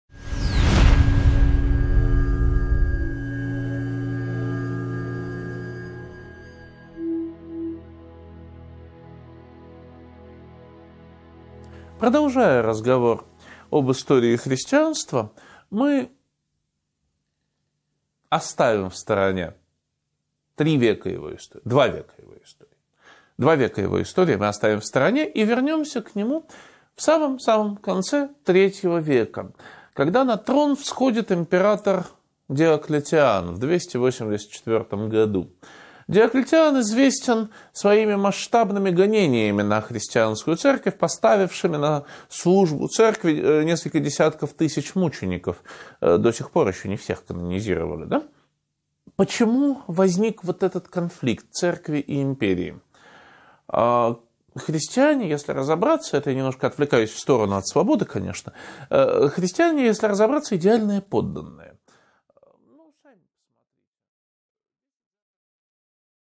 Аудиокнига 8.3 Христианство и проблема свободы (продолжение) | Библиотека аудиокниг